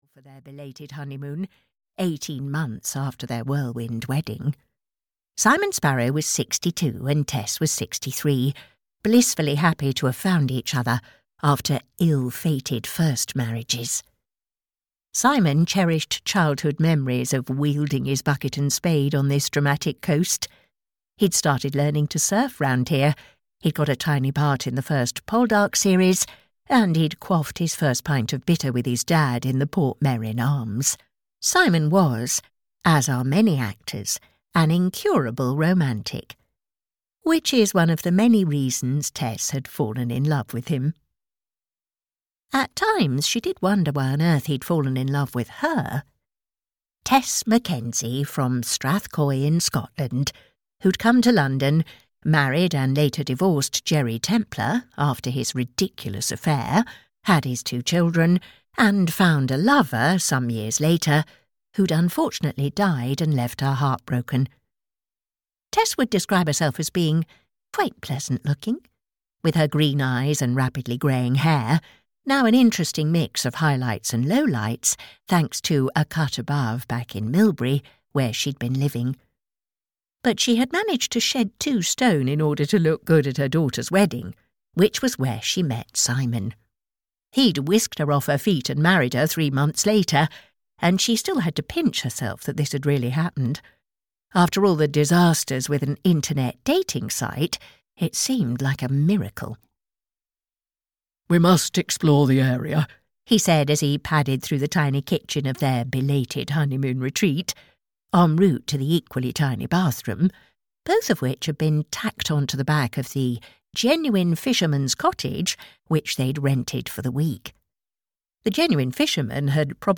The Golden Oldies Guesthouse (EN) audiokniha
Ukázka z knihy